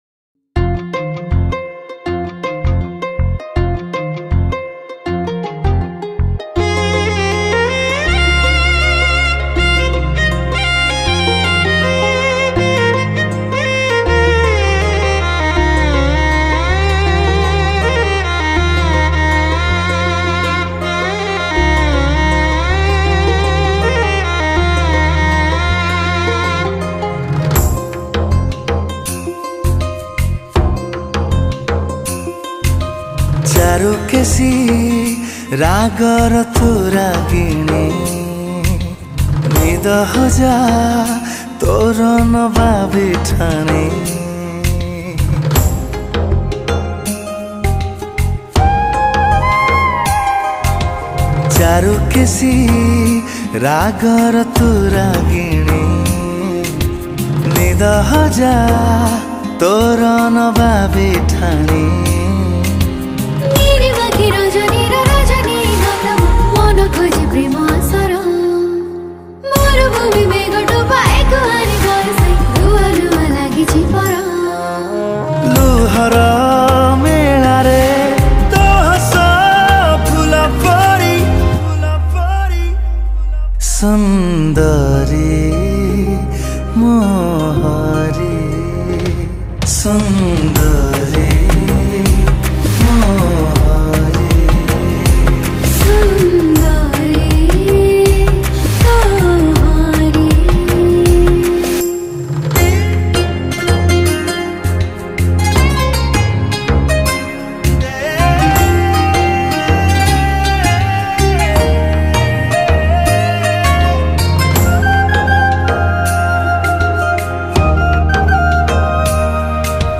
Romantic Song Music